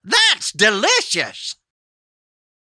Cartoon Characters – That’s Delicious, Male Voice
A silly and child-like voice-over, this clip gives you your very own “Tony the Tiger” slogan.
Apple-Hill-Studios-Cartoon-Characters-Thats-Delicious-Male-Voice-mp3.mp3